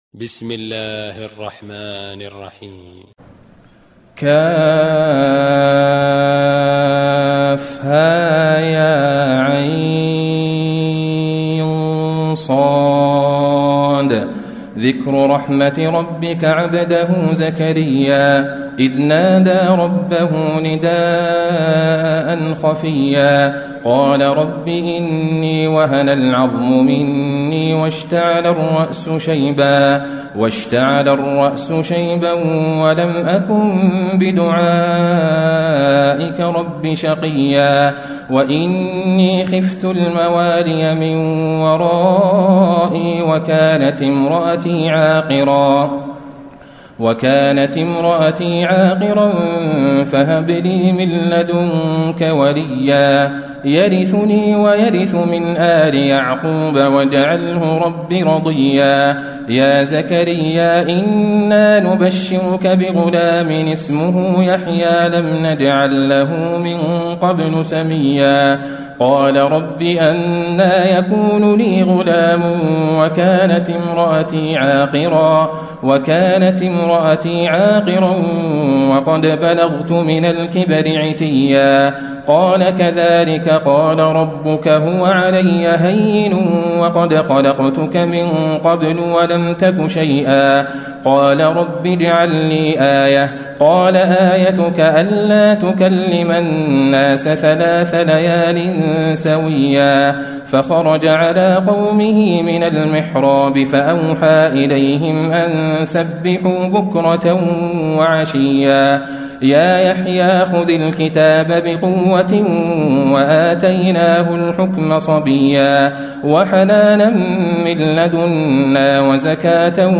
تراويح الليلة الخامسة عشر رمضان 1434هـ سورة مريم كاملة و طه (1-82) Taraweeh 15 st night Ramadan 1434H from Surah Maryam and Taa-Haa > تراويح الحرم النبوي عام 1434 🕌 > التراويح - تلاوات الحرمين